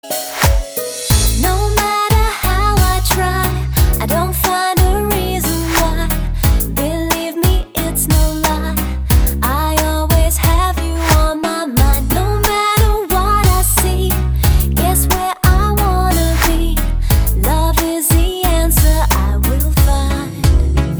лирика
медленные